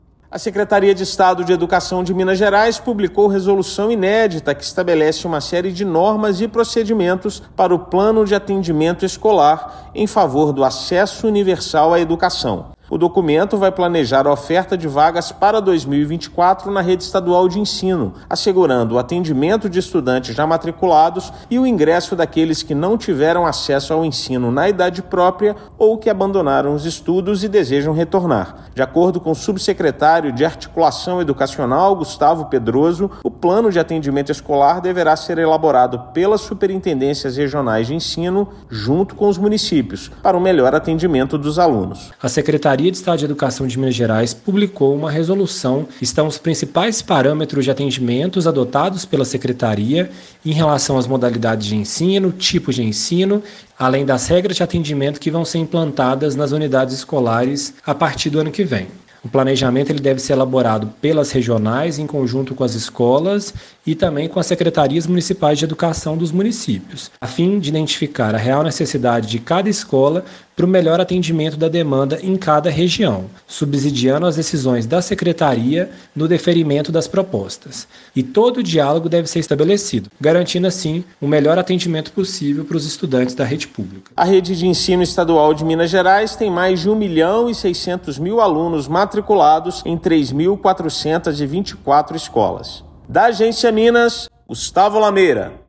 Normas fazem parte do Plano de Atendimento Escolar, que garante o direito à educação a quem deseja ingressar nas escolas da rede estadual. Ouça matéria de rádio.